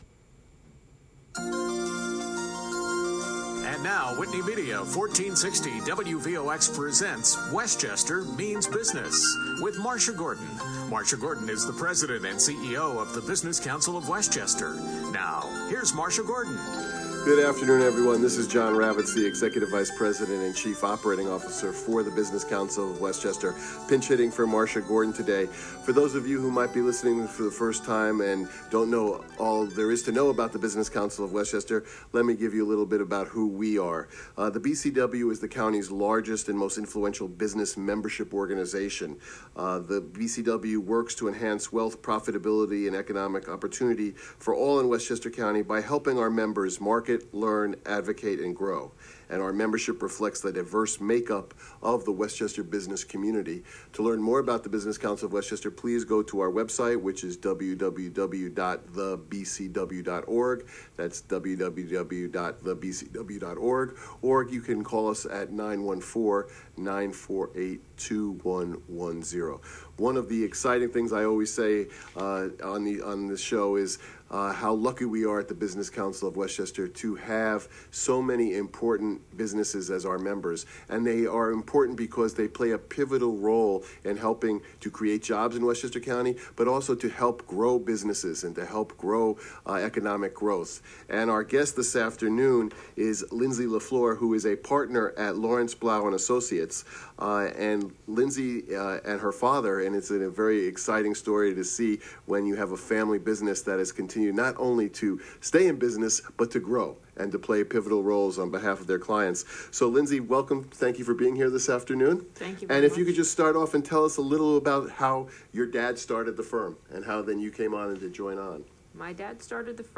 WVOX: Westchester Means Business Featured Radio Interview